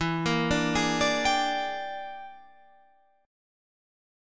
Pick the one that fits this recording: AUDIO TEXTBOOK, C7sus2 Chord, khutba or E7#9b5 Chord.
E7#9b5 Chord